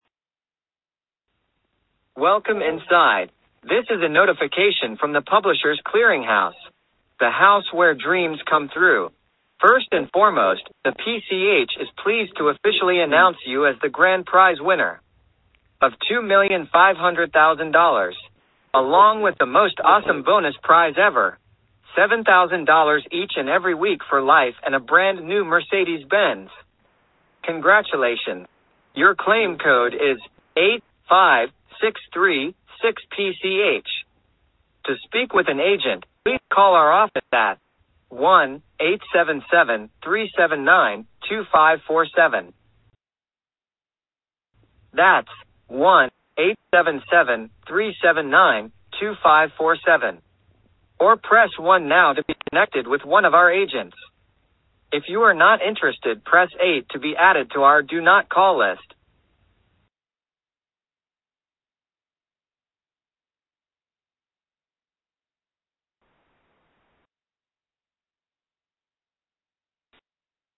Robocall :arrow_down_small: